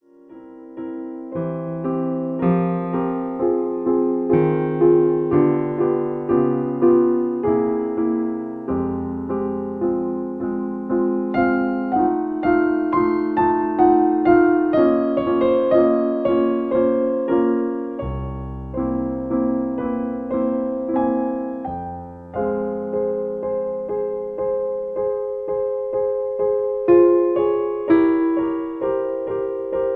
In F. Piano Accompaniment